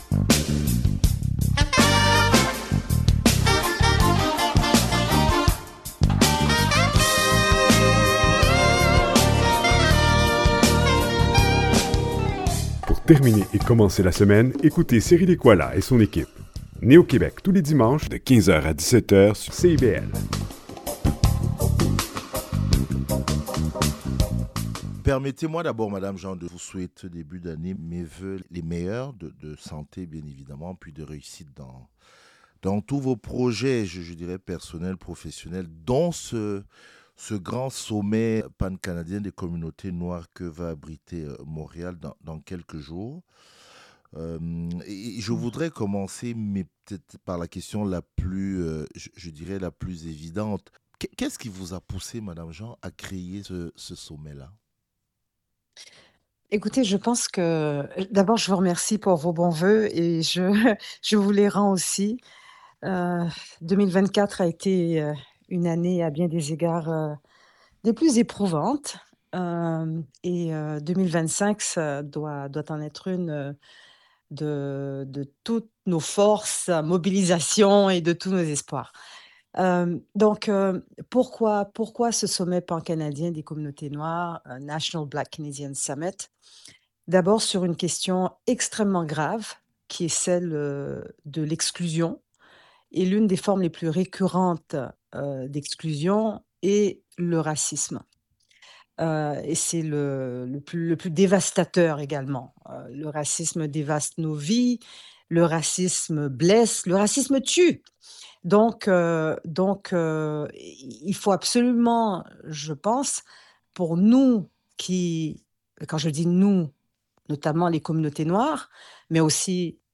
À cette occasion, Michaëlle Jean – dans une interview qu’elle nous a accordée – décrit le racisme systémique comme une violence structurelle qui engendre des déficits majeurs en termes de participation et de justice.
ENTREVUE-MICHAELLE-JEAN.mp3